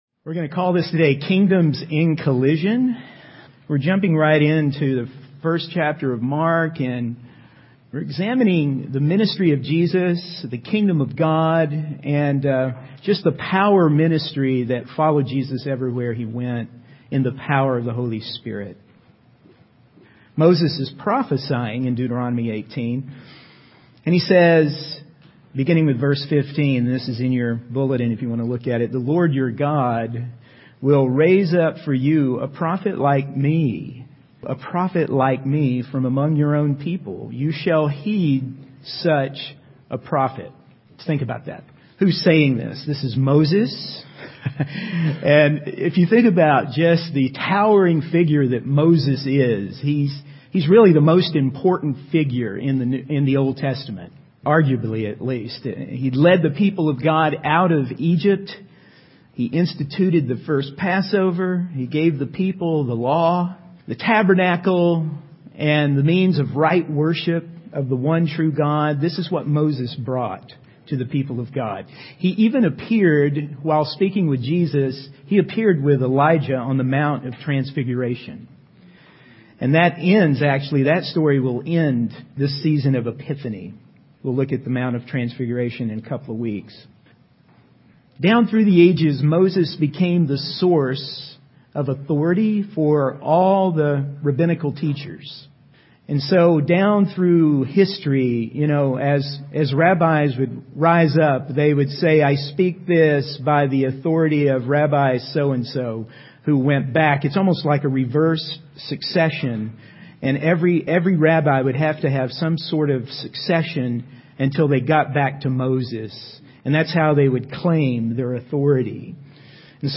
In this sermon, the preacher emphasizes the importance of the Holy Spirit in empowering the church to bring about positive change in their city.